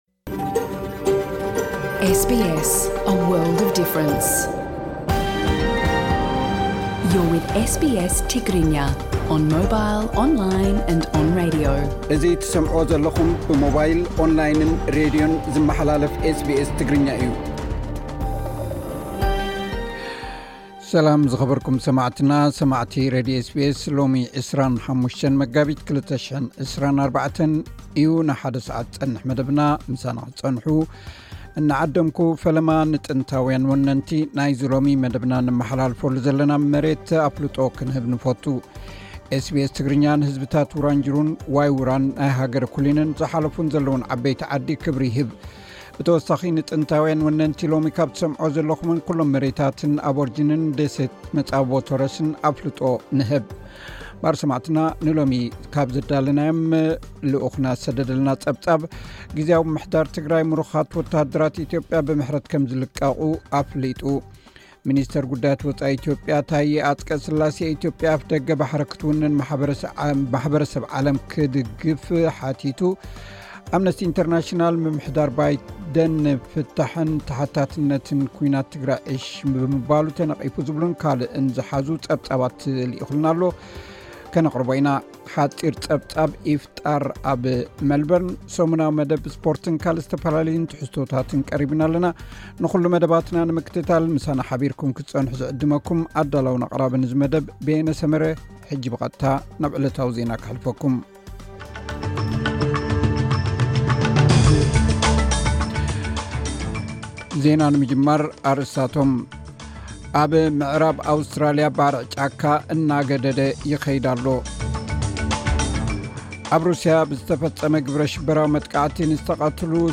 ዕለታዊ ዜና ኤስ ቢ ኤስ ትግርኛ (25 መጋቢት 2024)